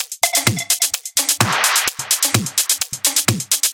VEH1 Fx Loops 128 BPM
VEH1 FX Loop - 39.wav